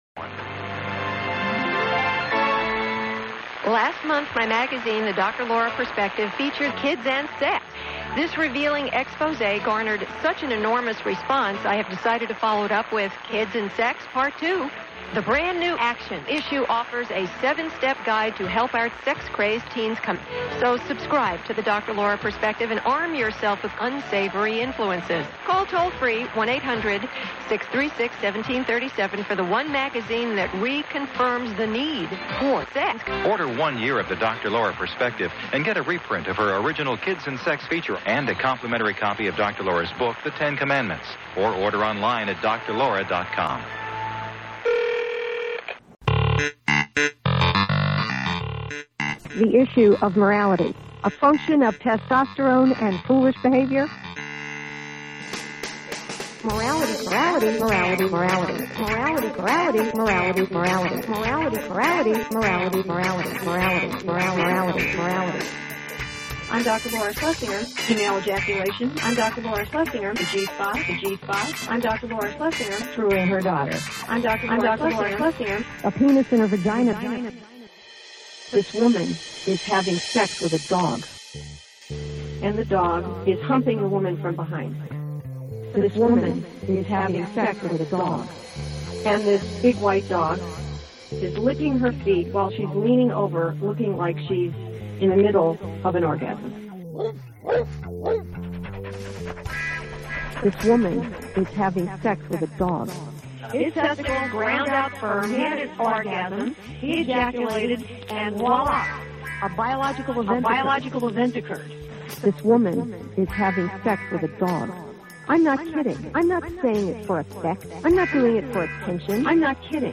Collage of Celebrity Speech
This is a gallery of collage derived from the utterings of media celebrities, created by artists from around the planet.
Creator: National Cynical Network